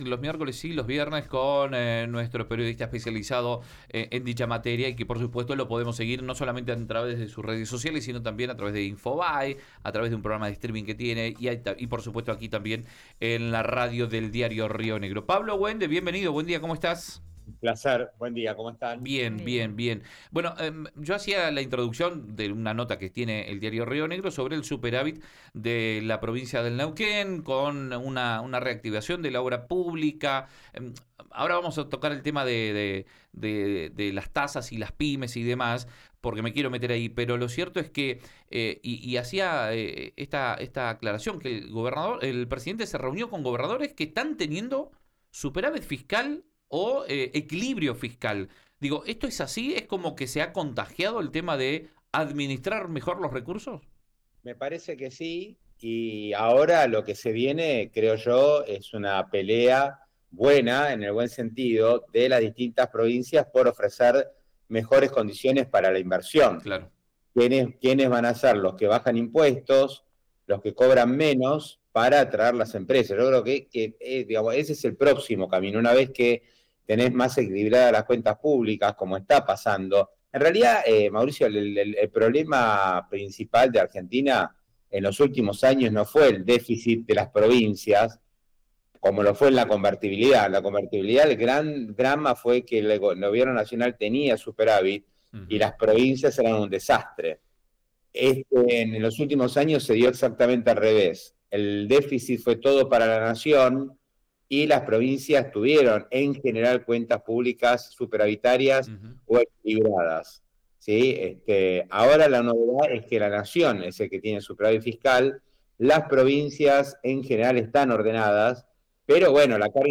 columna en Río Negro Radio